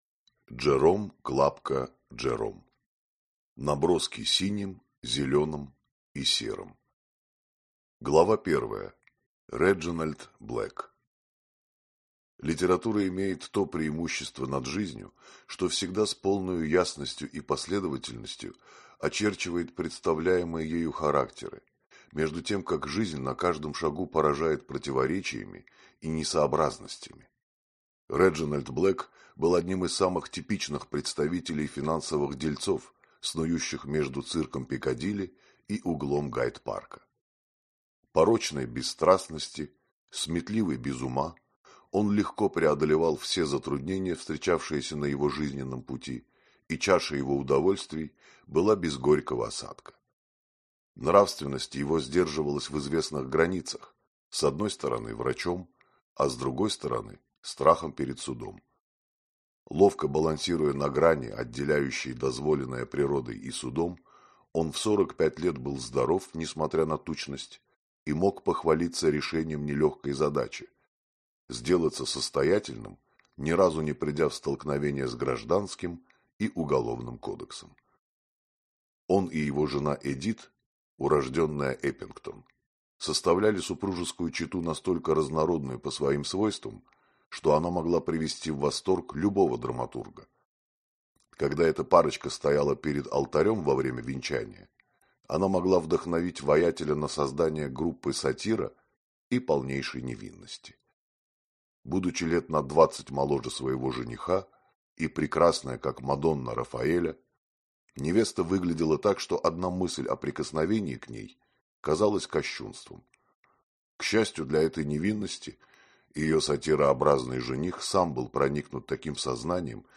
Аудиокнига Наброски синим, зеленым и серым | Библиотека аудиокниг